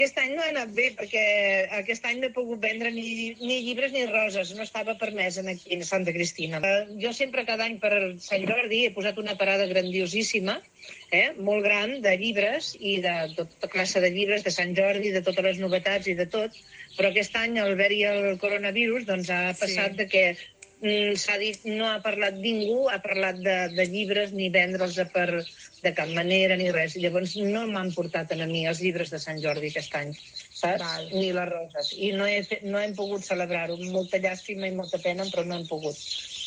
En el programa especial Sant Jordi que va emetre Ràdio Capital el dia de la diada a la tarda, vam parlar amb les llibreries per saber com han viscut aquest Sant Jordi.